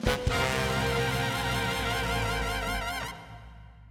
A song
Game rip